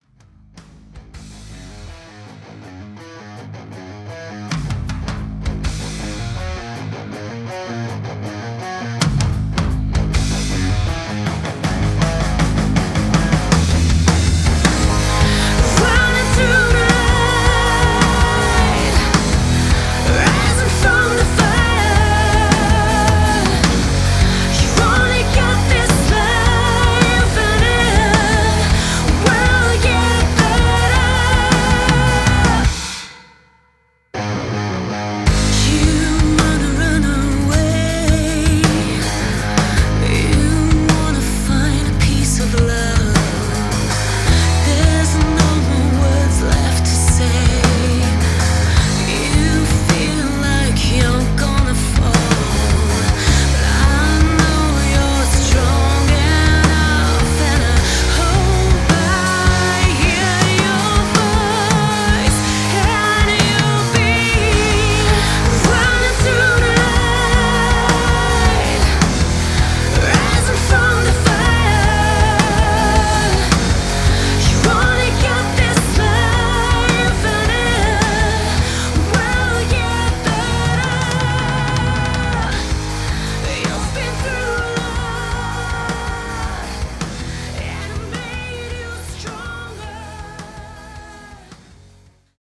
crystalline and captivating voice
Guitar
Bass
Drums